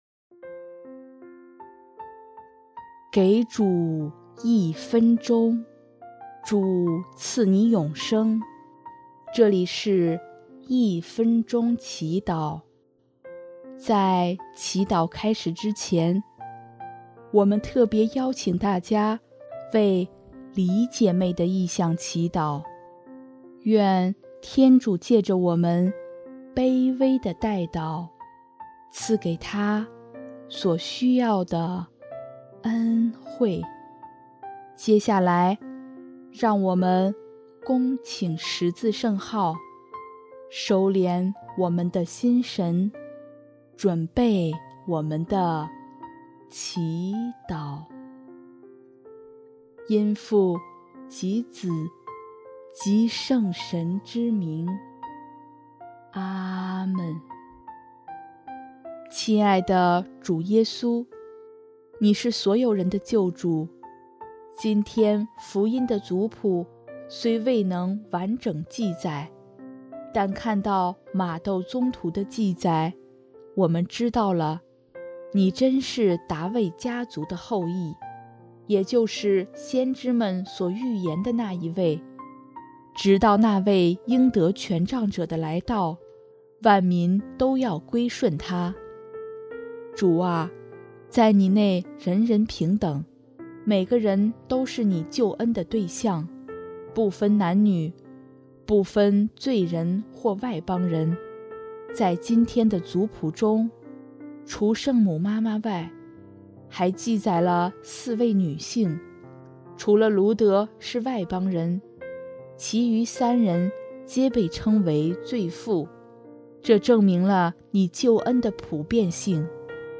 音乐： 第四届华语圣歌大赛参赛歌曲《感谢主恩歌》